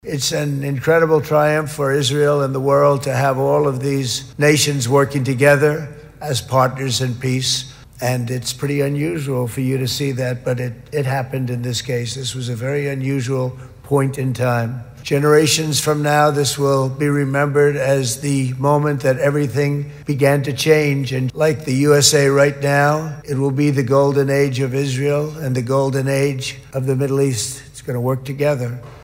Donald Trump, discurs în Parlamentul israelian: „Va fi epoca de aur a Israelului și epoca de aur a Orientului Mijlociu, care vor lucra împreună” | AUDIO
Președintele american Donald Trump a fost aplaudat în parlamentul israelian, după anunțul privind eliberarea tuturor celor 20 de ostatici israelieni ținuți captivi de Hamas timp de doi ani.
insert-trump-15-netradus.mp3